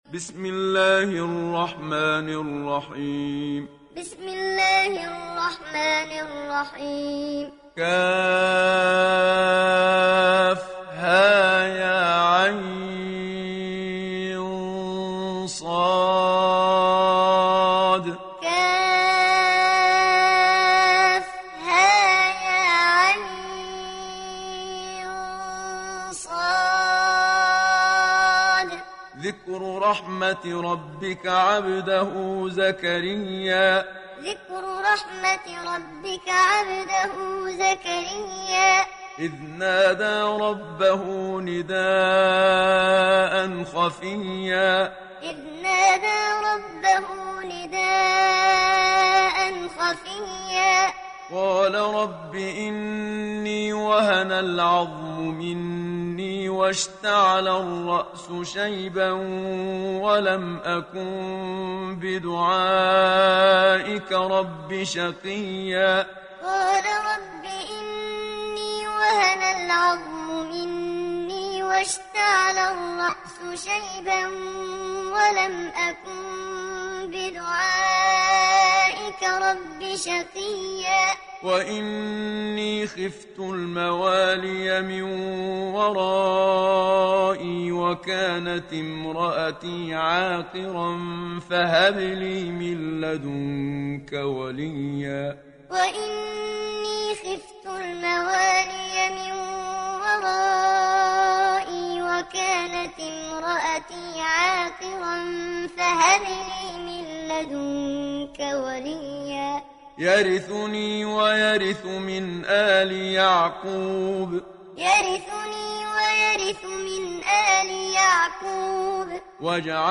Hafs an Assim
Moalem